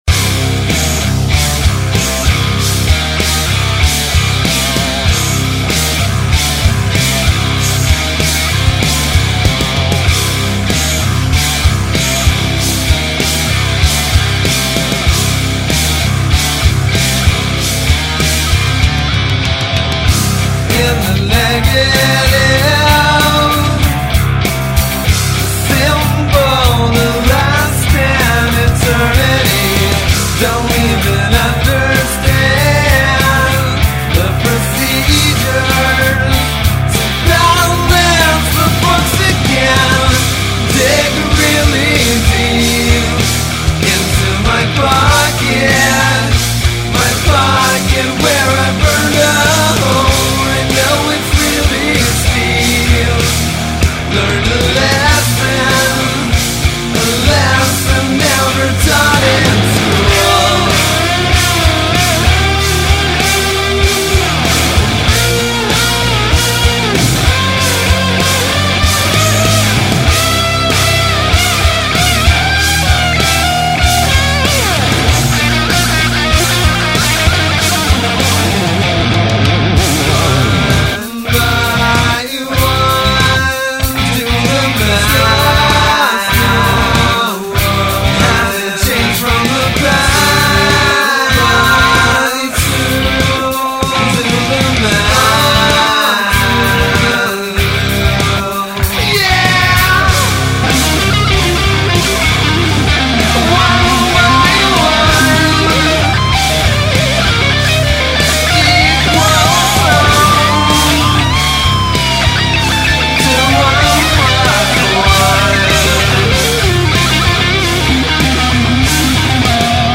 drummer
guitarist